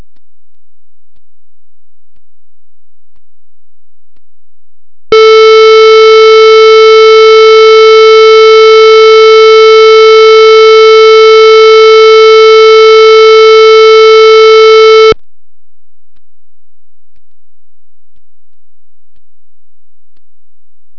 Bug 9750 – When nothing is playing, the silence is a bit louder than before.
Connect the left and right outputs of Ray to the input jack of sound card of the PC. 2.
The wav attachment is the recording of the streamming plus silence.